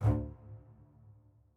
strings13_2.ogg